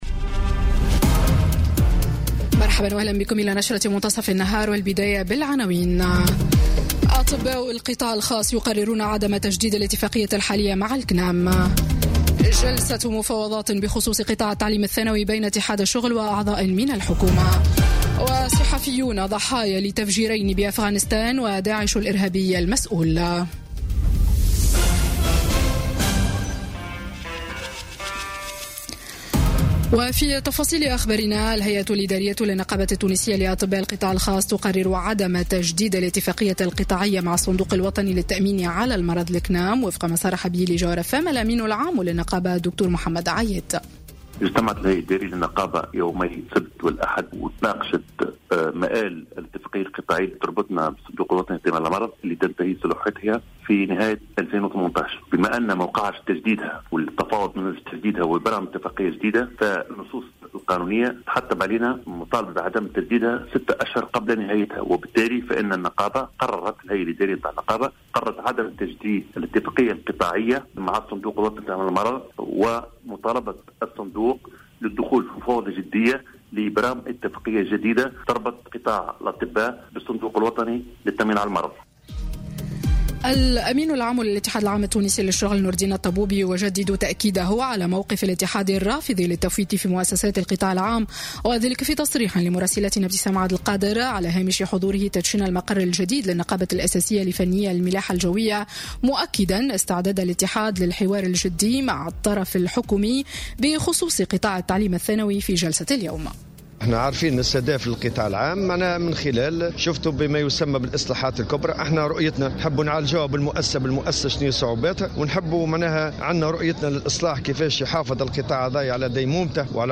Journal Info 12h00 du lundi 30 Avril 2018